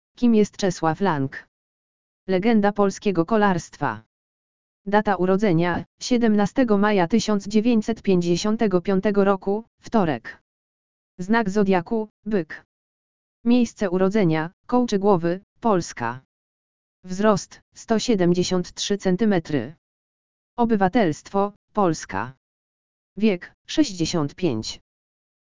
lektor_audio_urodziny_czeslaw_lang.mp3